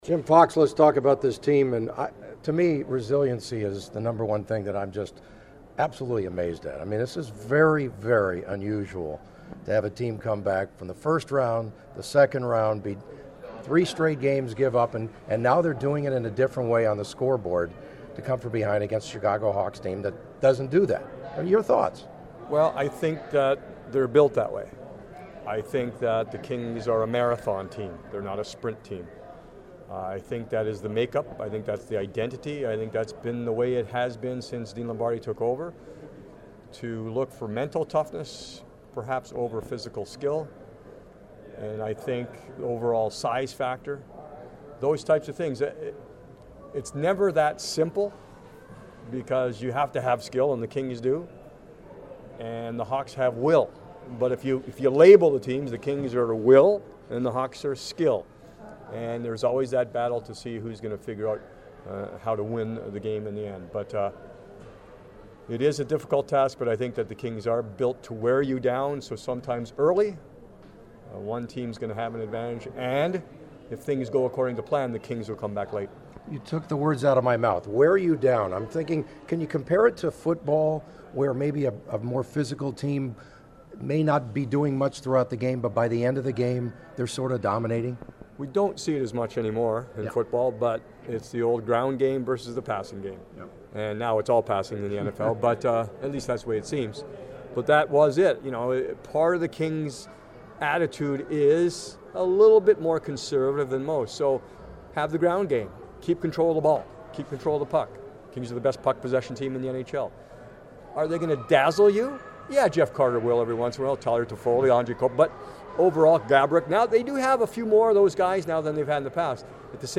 The Kings lead the Chicago Blackhawks 2 games to 1 in their Western Conference final. After Sunday’s practice in El Segundo